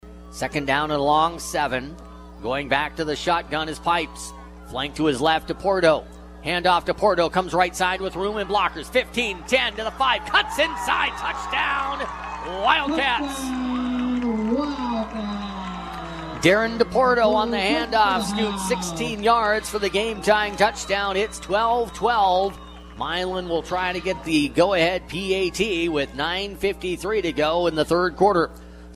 had the play-by-play call